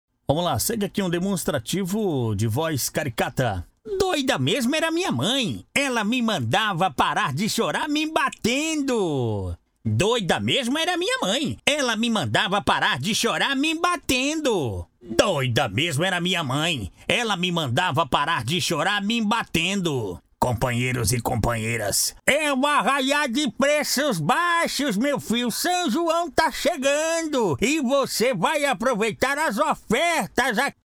VOZ CARICATO :